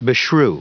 Prononciation du mot beshrew en anglais (fichier audio)
Prononciation du mot : beshrew